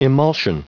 Prononciation du mot emulsion en anglais (fichier audio)
Prononciation du mot : emulsion